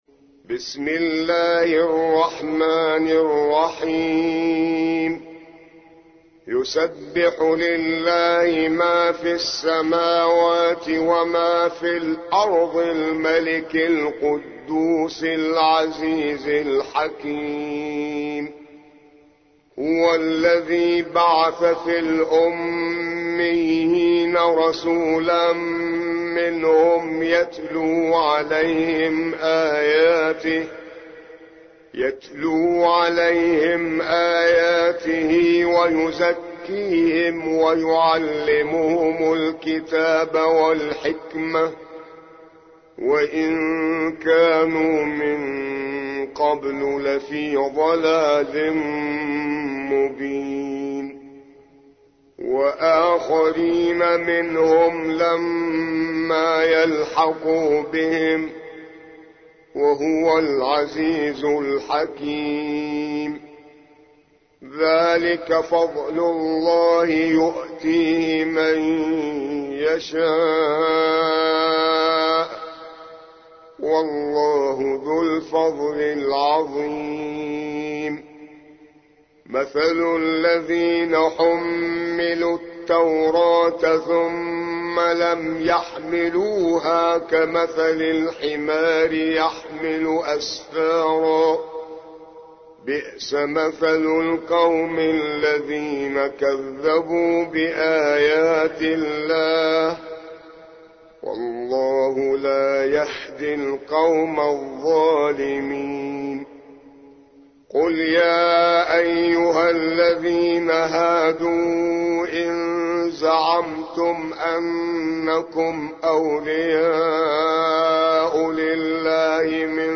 62. سورة الجمعة / القارئ